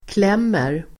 Uttal: [kl'em:er]